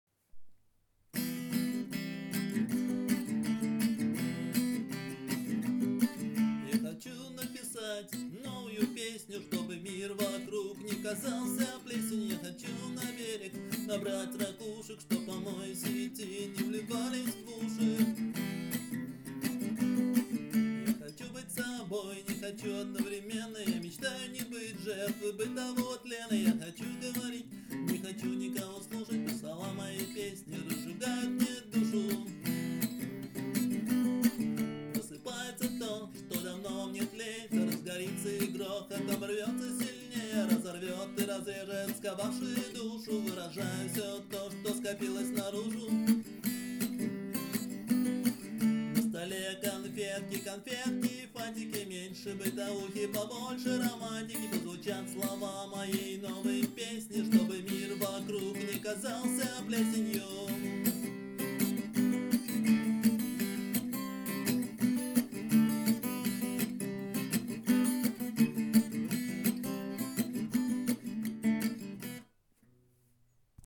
Панк (2402)